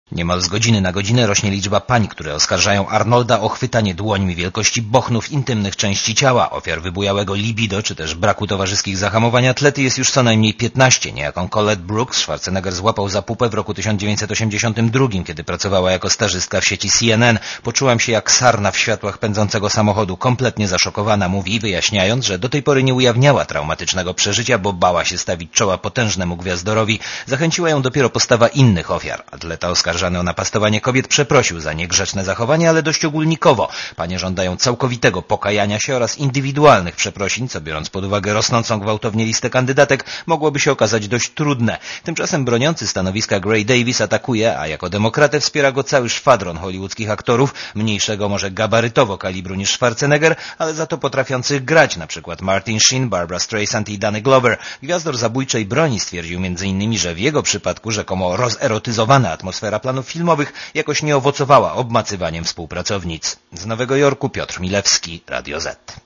Posłuchaj relacji korespondenta Radia Zet (260 KB)